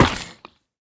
Minecraft Version Minecraft Version snapshot Latest Release | Latest Snapshot snapshot / assets / minecraft / sounds / mob / turtle / hurt3.ogg Compare With Compare With Latest Release | Latest Snapshot
hurt3.ogg